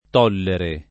tollere [ t 0 llere ]